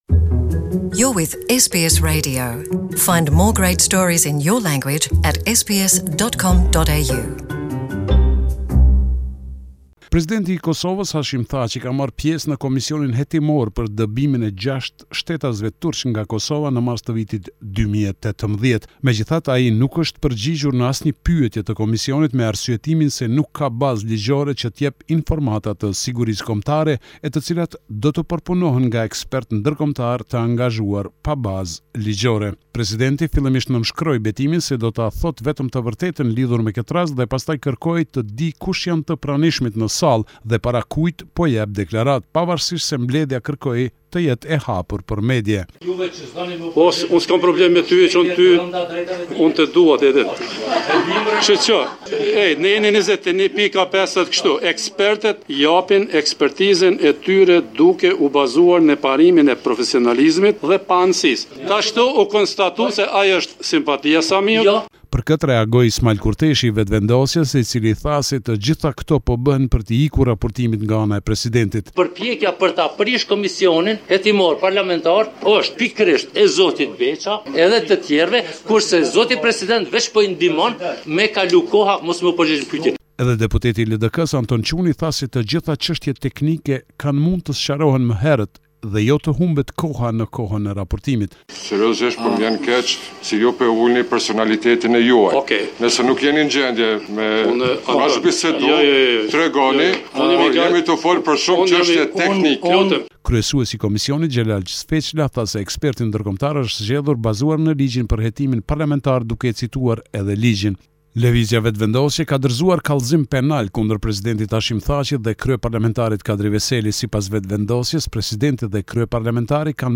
This is a report summarising the latest developments in news and current affairs in Kosova